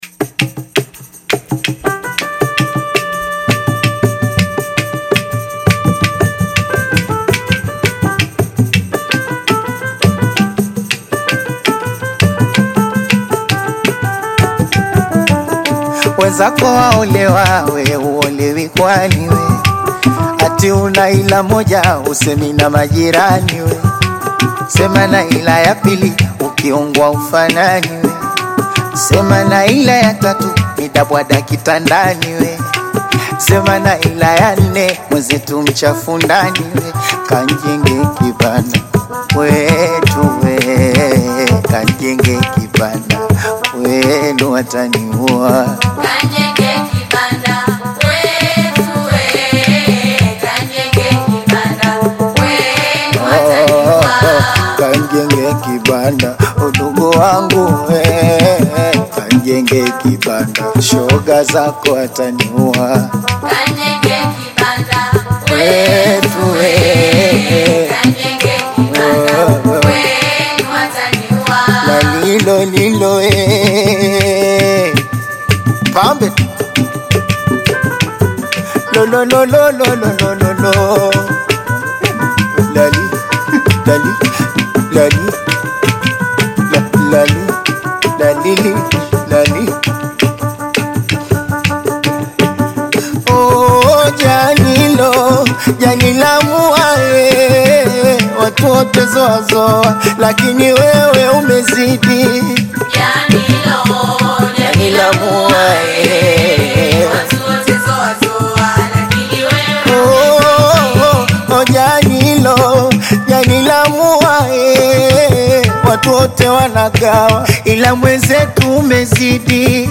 Afro-Pop/Bongo Flava single
rhythm-driven hooks